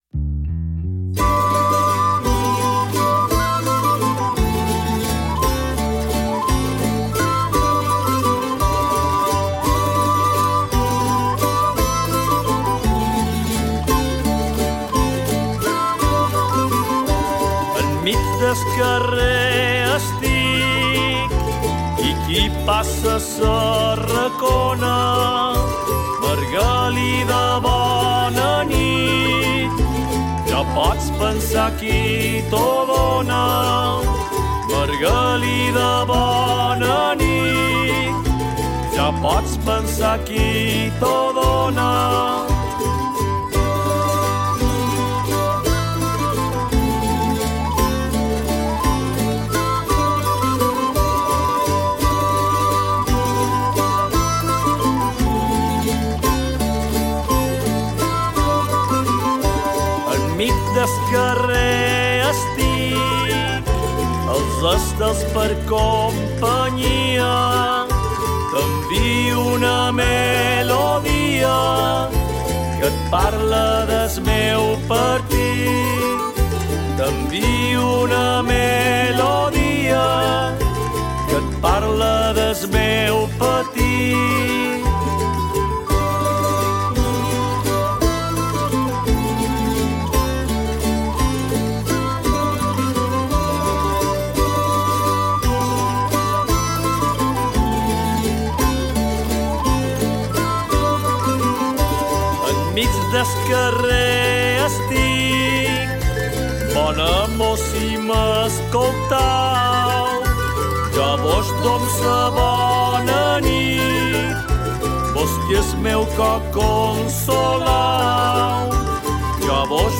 Així mateix també vos deixam amb la versió que n'interpreta Sarau Alcudienc, basada en la tonada i la lletra populars recollides al nostre poble.
16-Valset-alcudienc.mp3